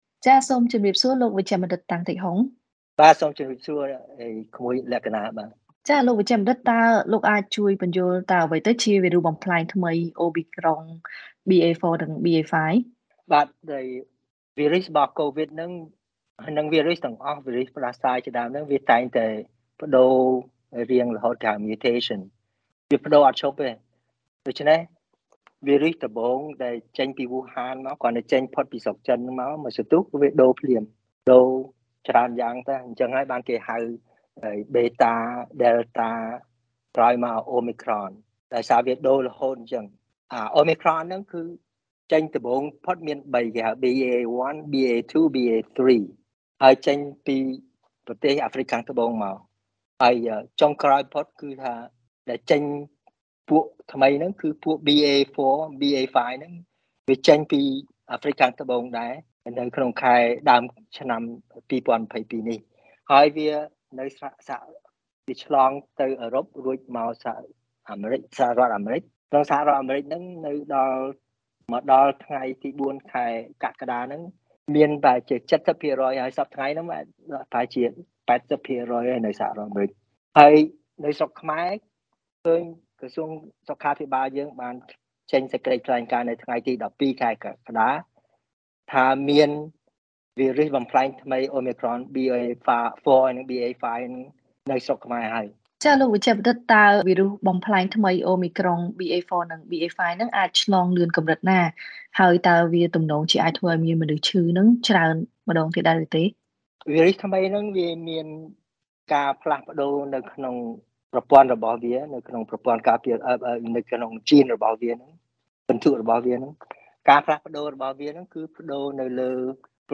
បទសម្ភាសន៍ VOA៖ វីរុសអូមីក្រុងបំប្លែងថ្មី BA4 និង BA5 ឆ្លងលឿនជាងមុន ប៉ុន្តែមានស្ថានភាពជំងឺស្រាល